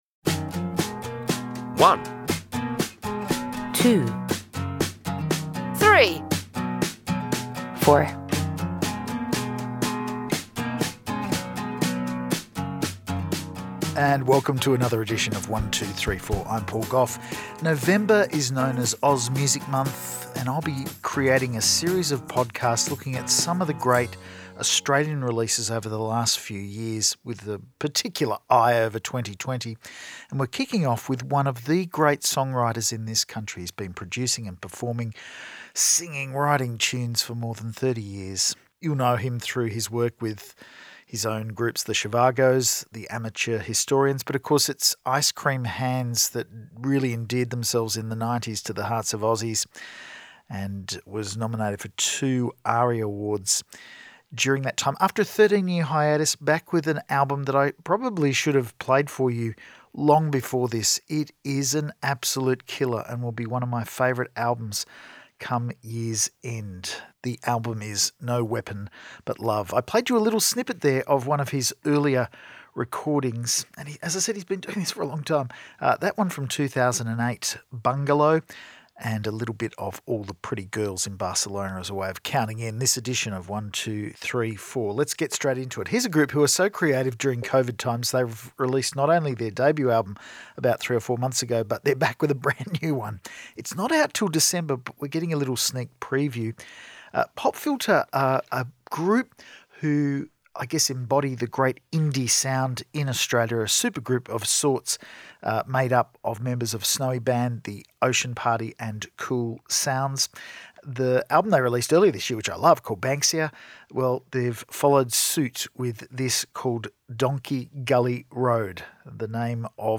harmony drenched, classic guitar driven melodic rock
power pop